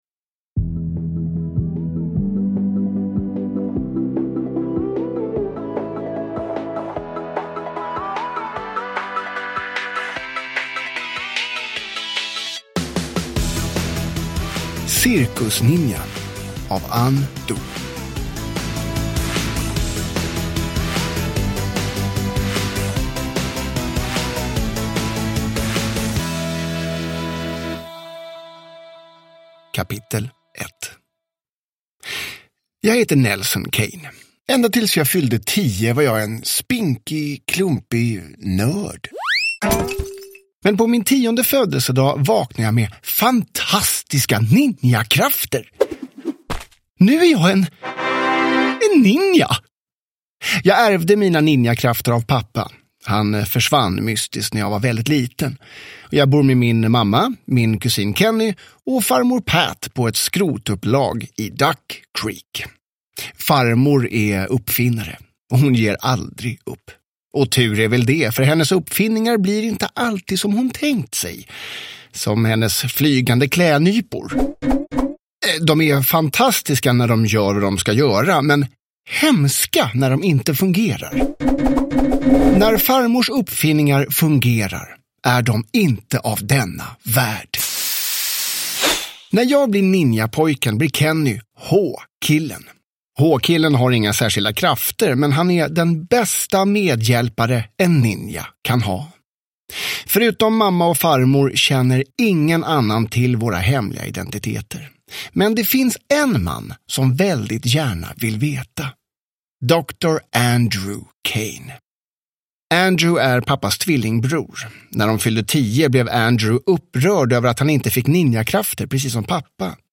Cirkusninjan – Ljudbok – Laddas ner